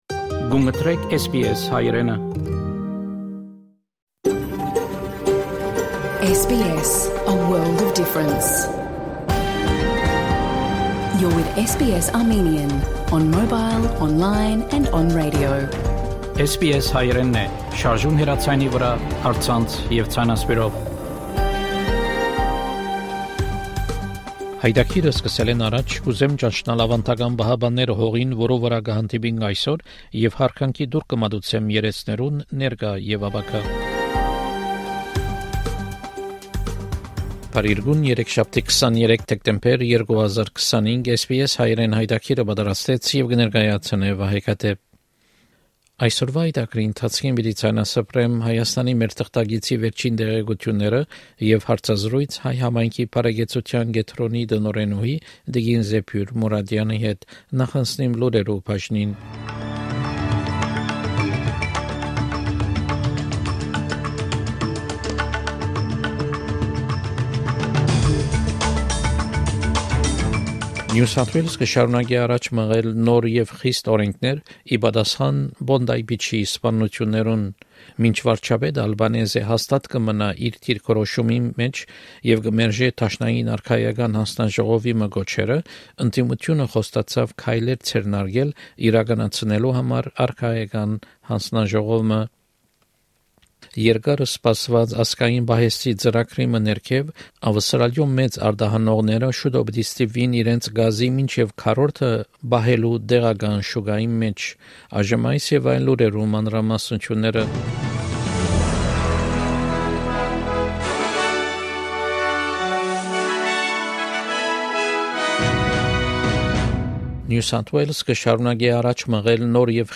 SBS Armenian news bulletin from 23 December 2025 program.